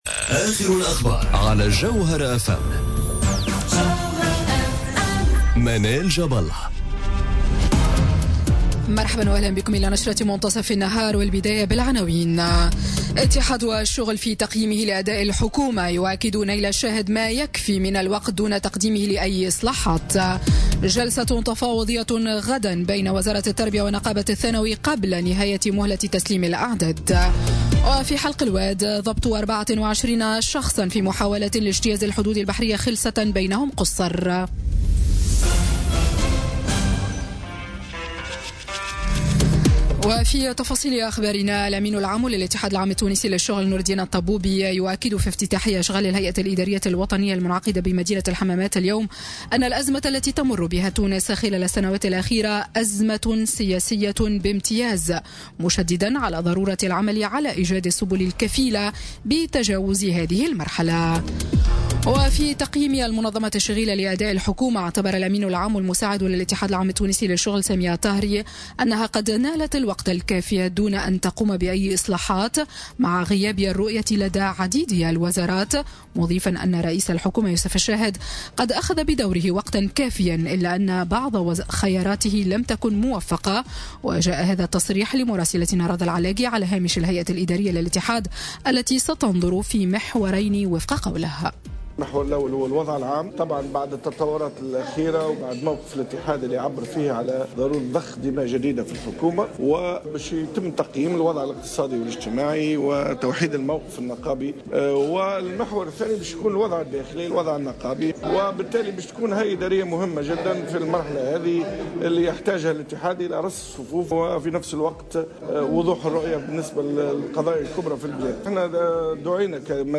نشرة أخبار منتصف النهار ليوم الاحد 11 مارس 2018